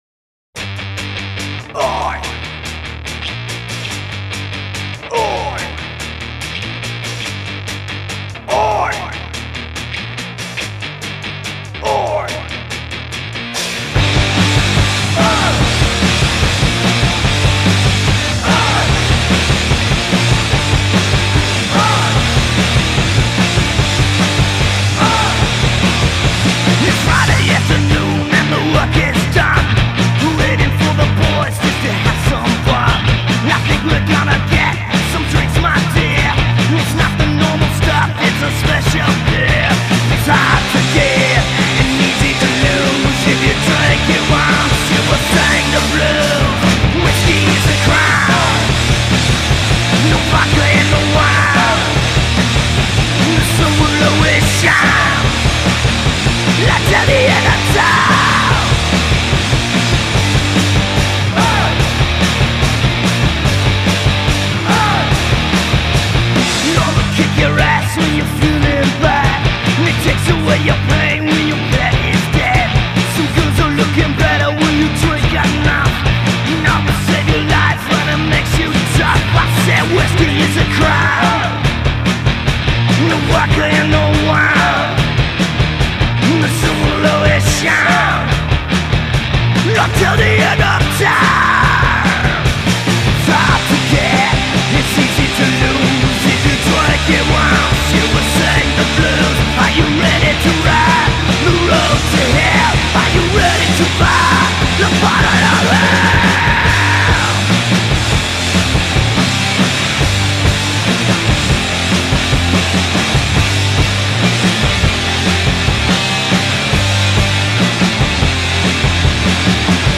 recorded a fantastic coverversion of
Scum Rock